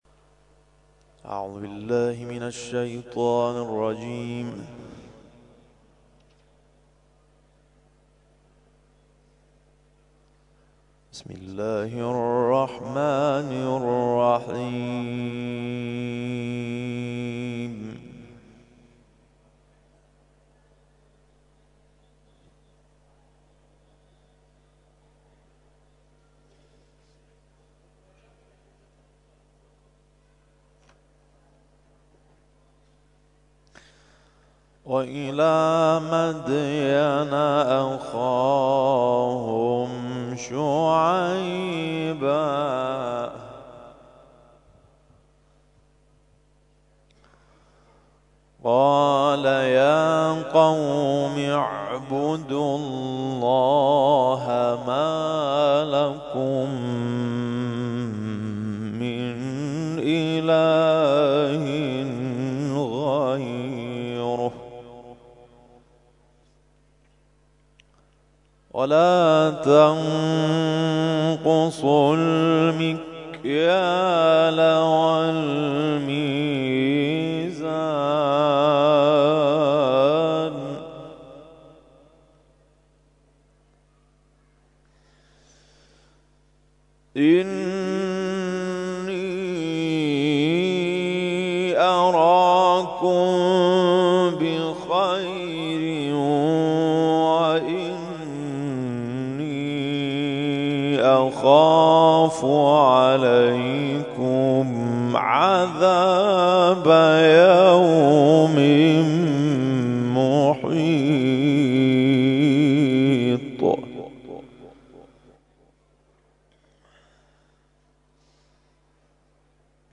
تلاوت ظهر - سوره هود آیات (84 الی 86) و سوره بینه آیات (7 الی 8)